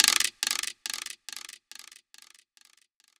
OTF Rim.wav